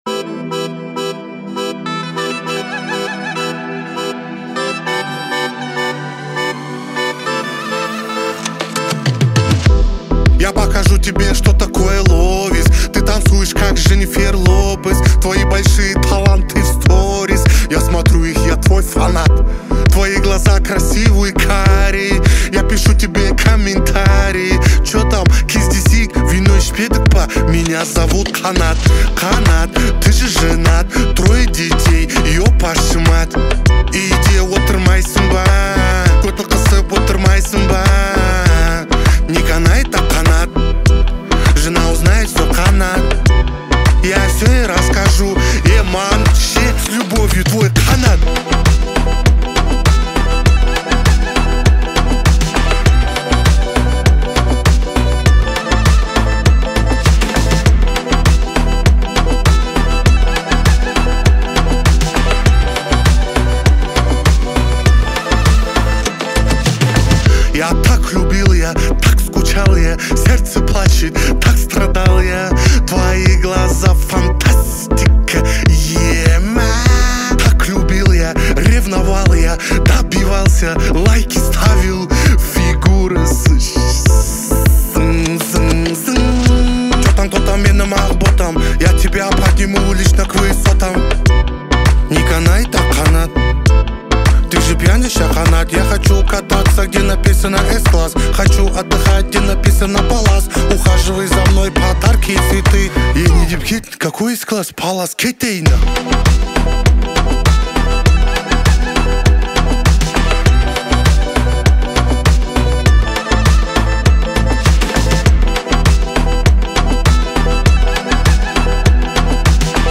Популярная музыка от нейросети